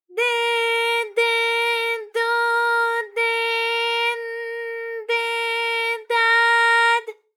ALYS-DB-001-JPN - First Japanese UTAU vocal library of ALYS.
de_de_do_de_n_de_da_d.wav